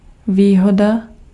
Ääntäminen
Ääntäminen France: IPA: [a.tu] Haettu sana löytyi näillä lähdekielillä: ranska Käännös Ääninäyte Substantiivit 1. výhoda {f} Muut/tuntemattomat 2. aktivum {n} 3. trumfy {m} 4. trumf {m} Suku: m .